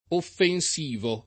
offensivo [ offen S& vo ] agg.